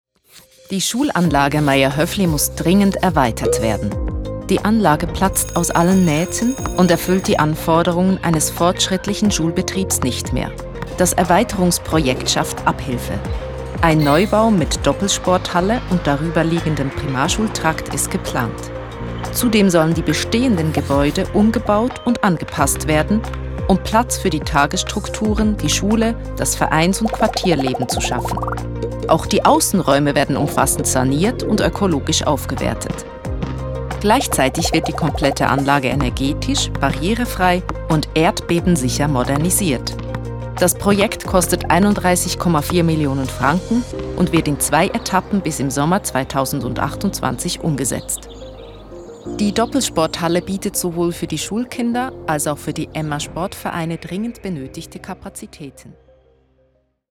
OFF-Kommentar Hochdeutsch (CH)